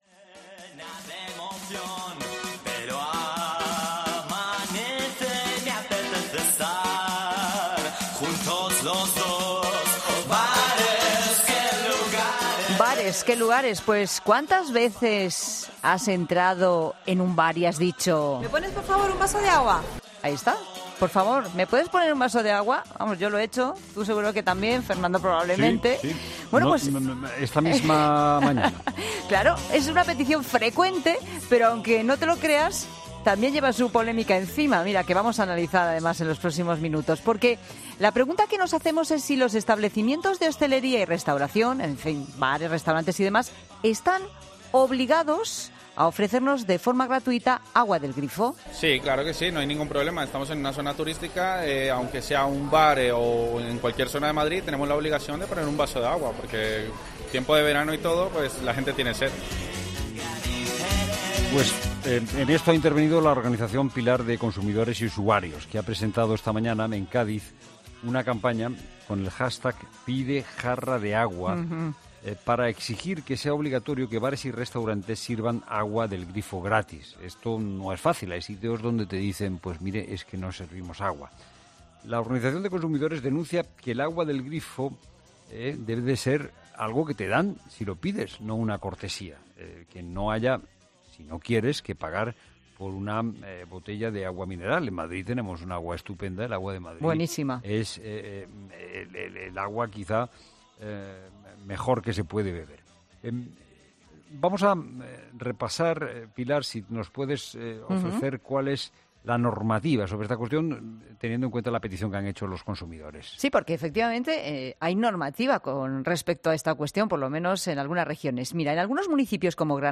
Hablamos en 'La Tarde'